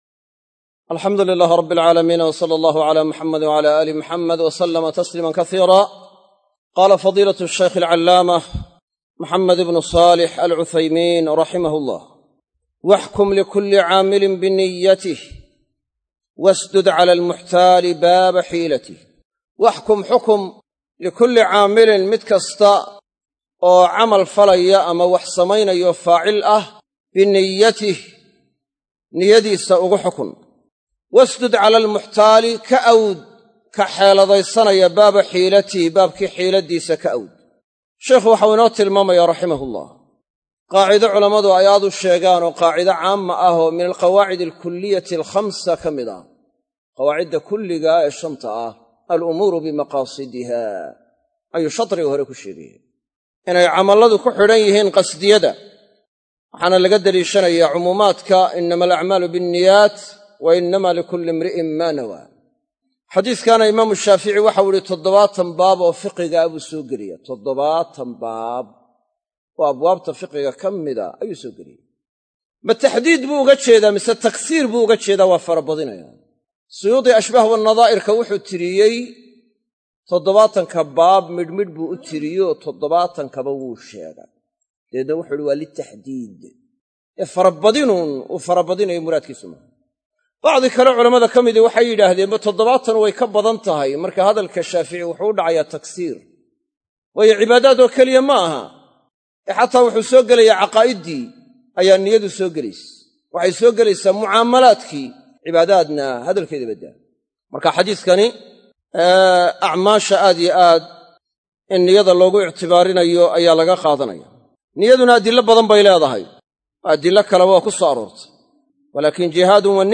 Darsiga 7aad